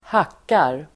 Uttal: [²h'ak:ar]